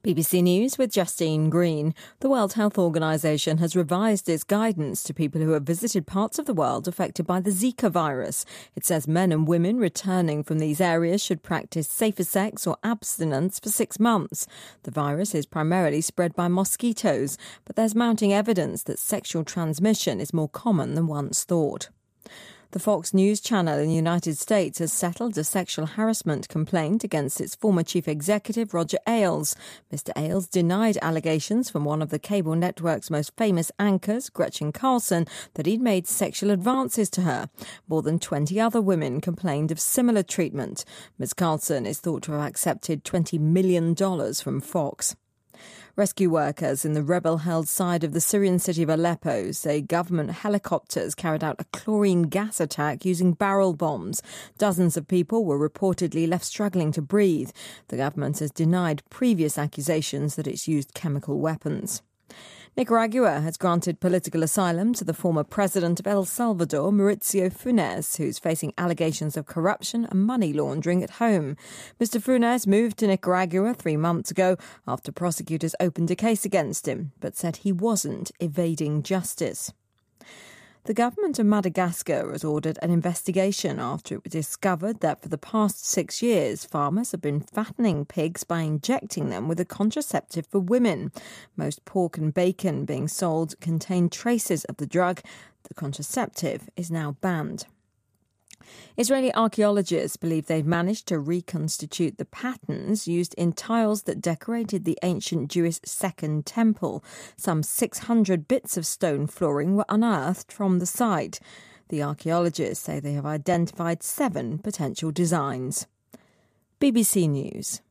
日期:2016-09-08来源:BBC新闻听力 编辑:给力英语BBC频道